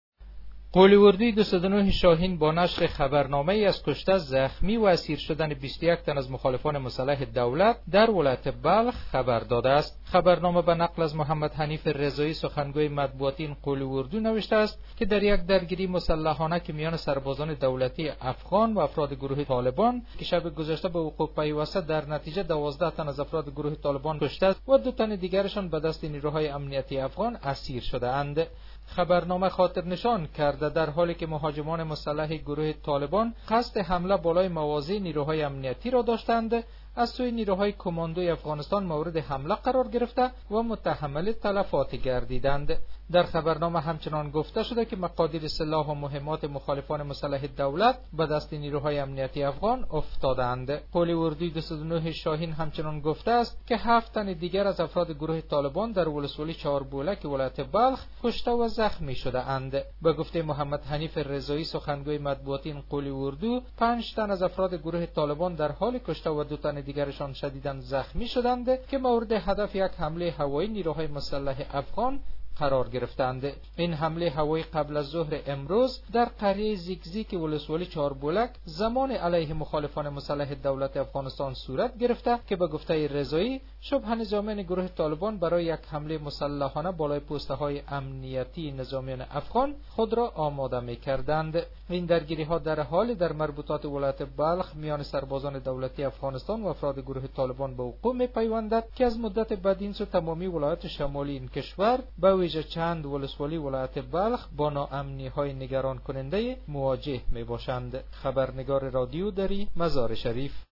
گزارش تکمیلی این خبر